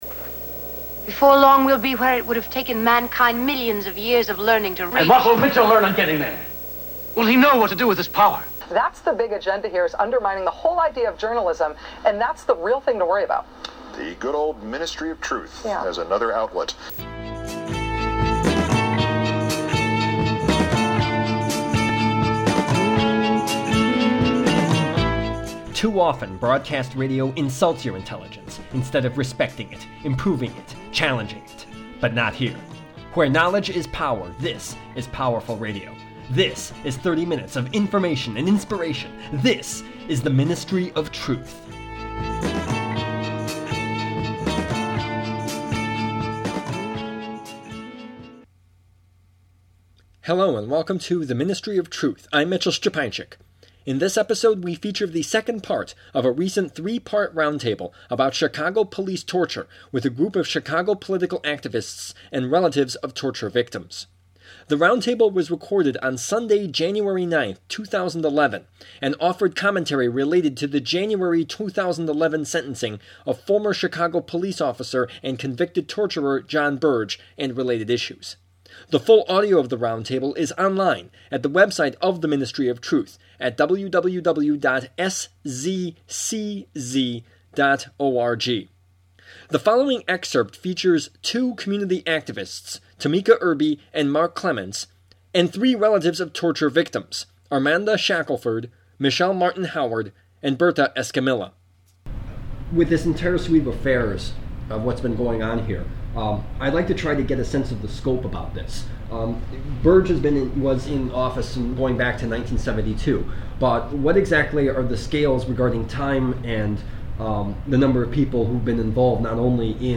The Ministry of Truth: Excerpt #2 of 2011 Roundtable on Torture by Chicago Police